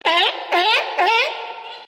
Sealion - Морской котик
Отличного качества, без посторонних шумов.
201_sealion.mp3